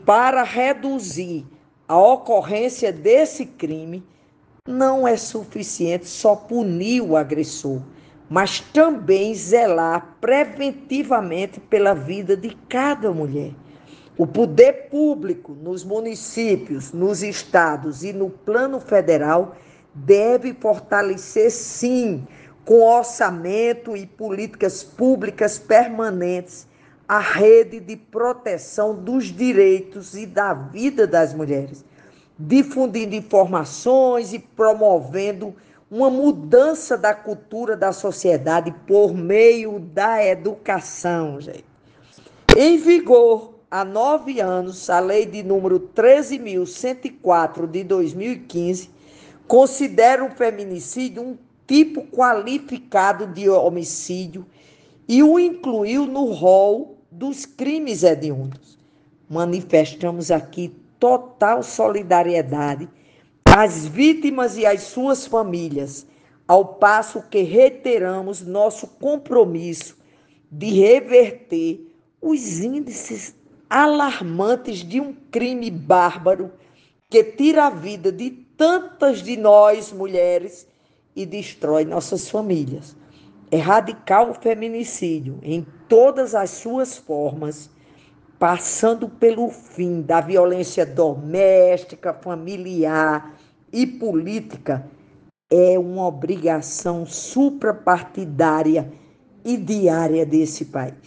Ouça áudio de Zenaide defendendo a nova lei: